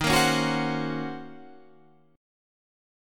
D#m13 Chord
Listen to D#m13 strummed